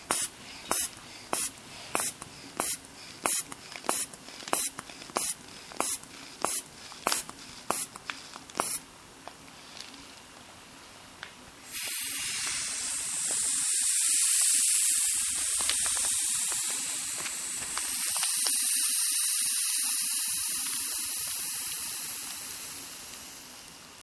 Tensiomètre (à poire)